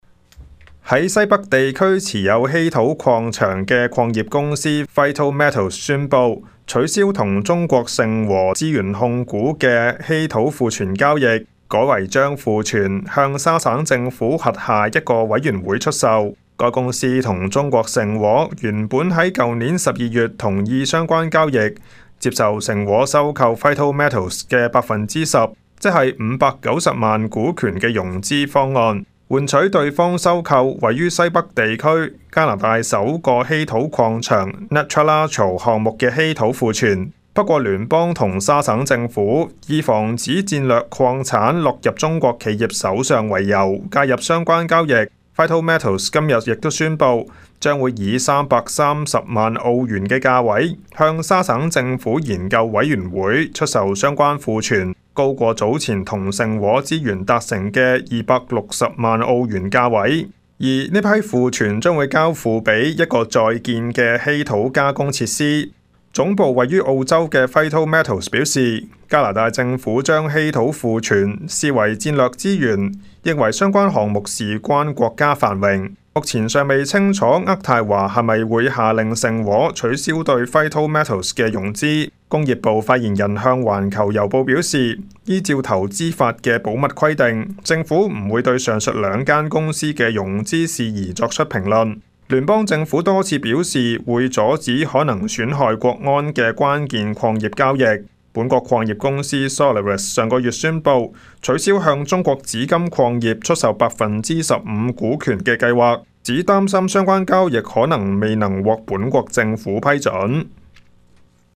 news_clip_19334.mp3